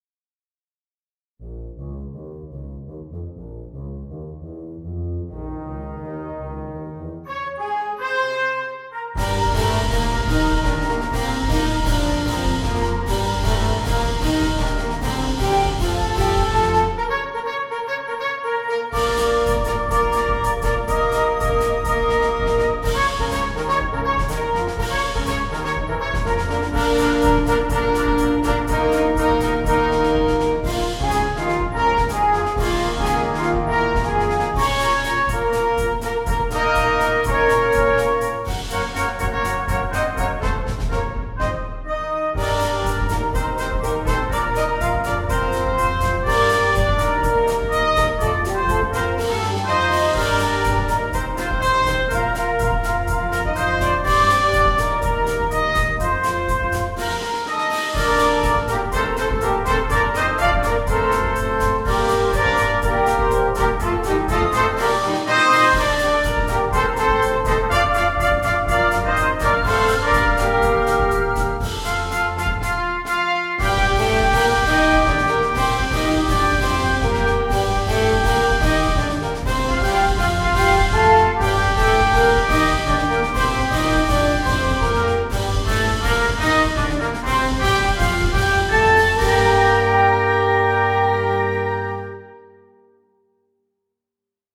Virtual recordings were made using NotePerfomer 3.